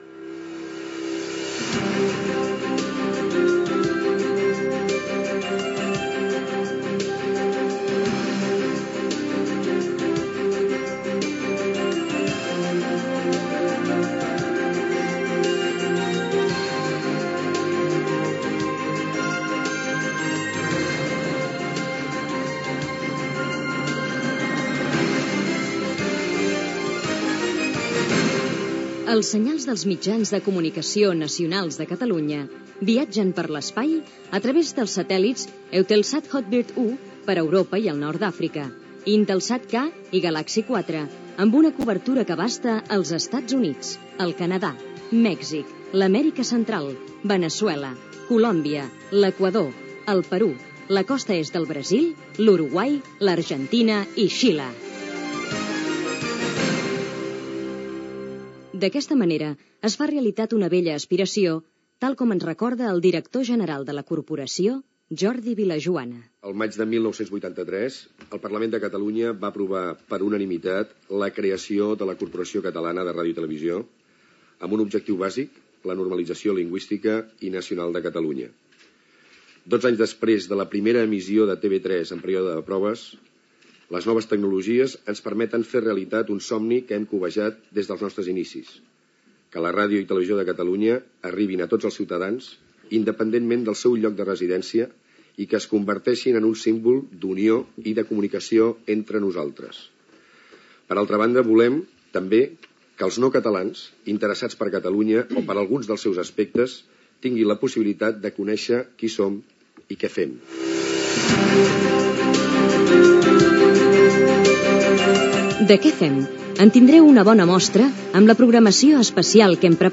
Paraules de Jordi Vilajoana, director de la Corporació Catalana de Ràdio i Televisió.